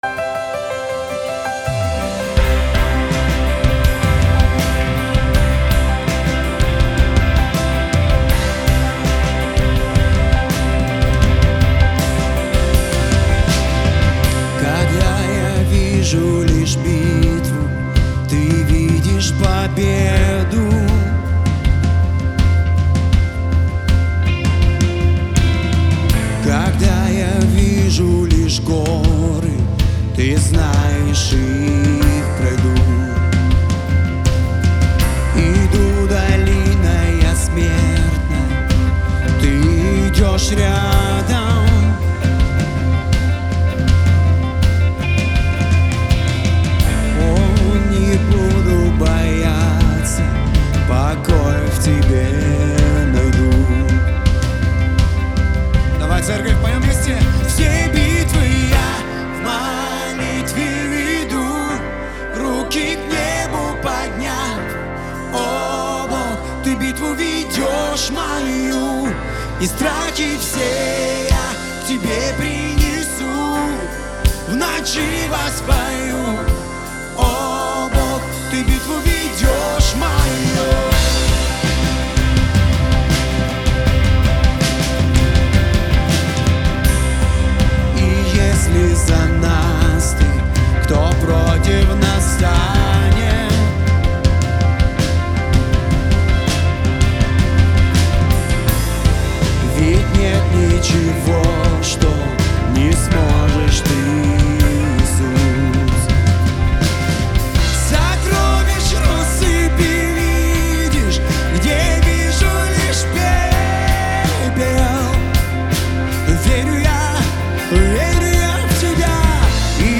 272 просмотра 120 прослушиваний 4 скачивания BPM: 162